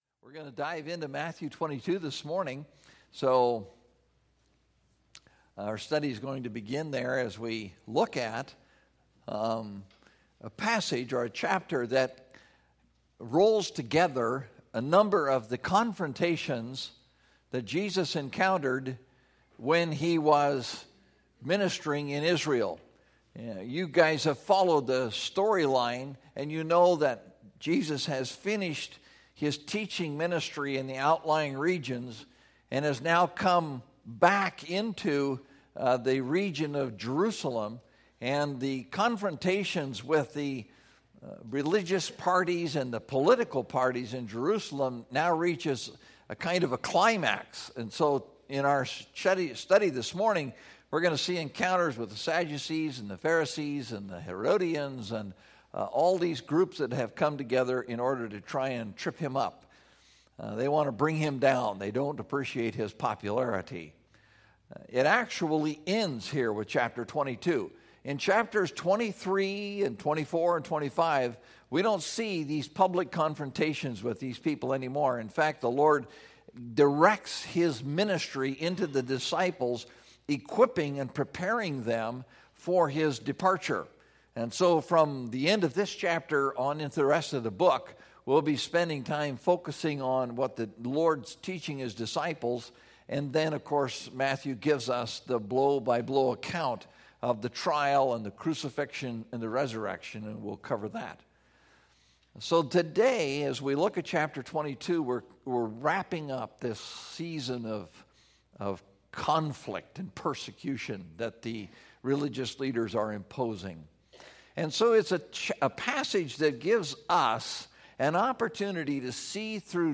Become Like Christ (Matthew 22:1-46) – Mountain View Baptist Church